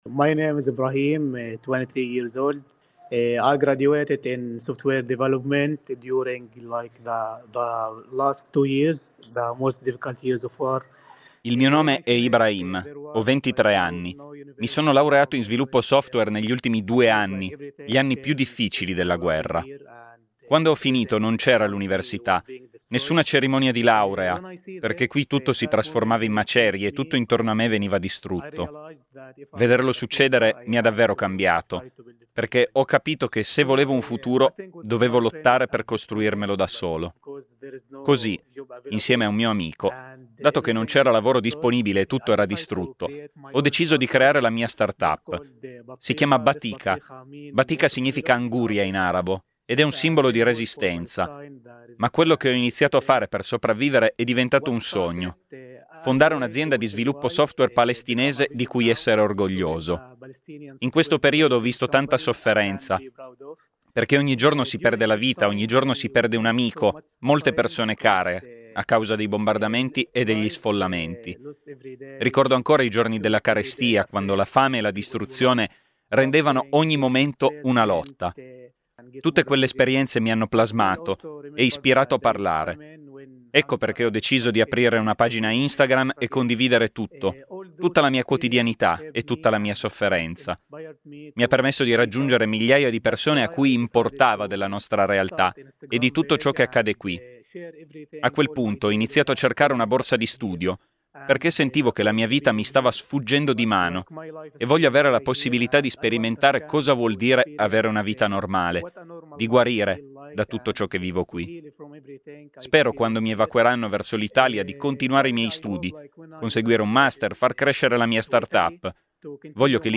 Come sentirete dalle loro voci, ci sperano ancora e fanno di tutto per essere pronti.
Intervista